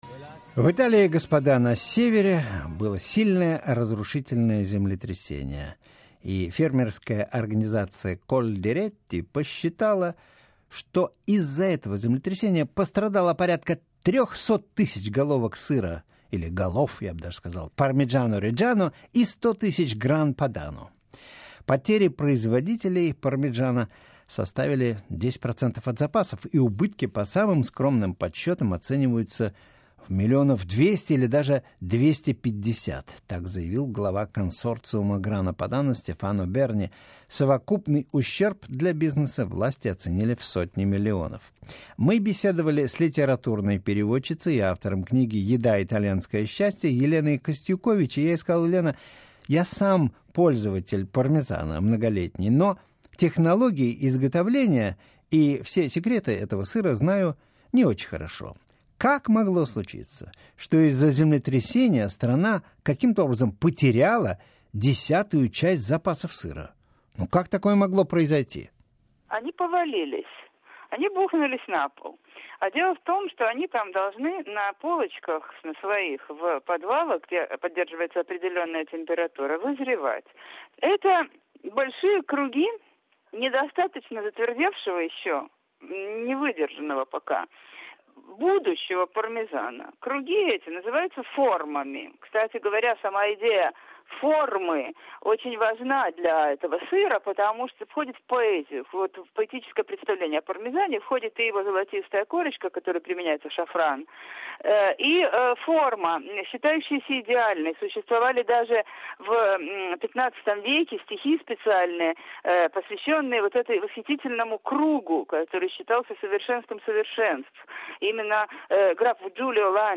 В программе БибиСева автор книги "Еда. Итальянское счастье" Елена Костюкович поговорила с Севой Новгороцевым о традиционных тонкостях технологии изготовления этого сыра и о том, каким образом в Италии спасают пармезан от последствий землетрясения.